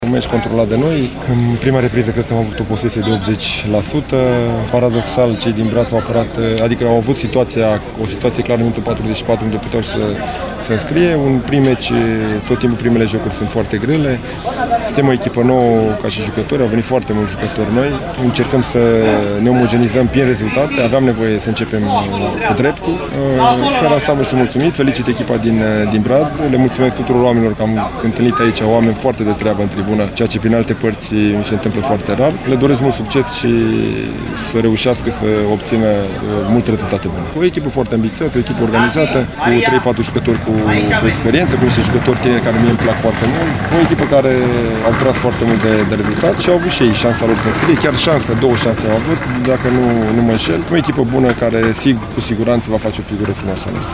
Mulțumit de victorie s-a declarat la final antrenorul cărășenilor, Dan Alexa: